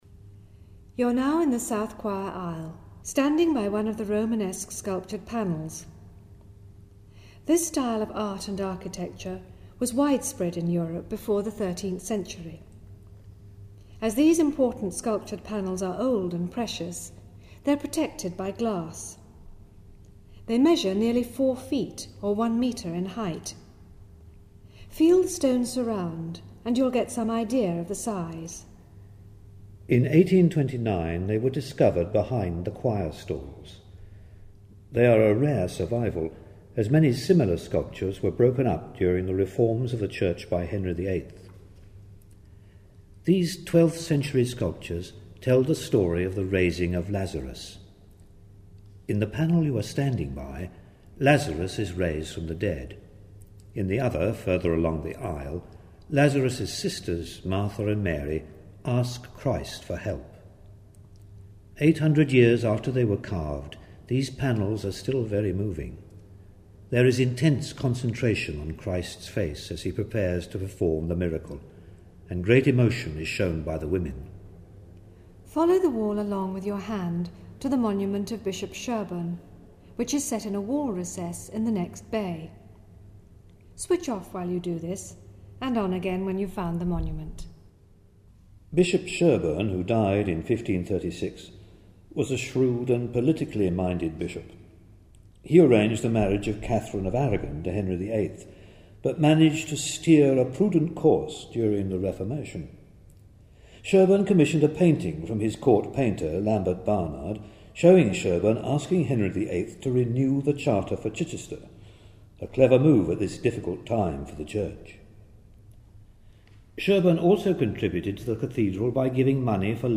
An Acoustic Fingerprint Guide of Chichester Cathedral - 4: South Choir Aisle
4-south-choir-aisle.mp3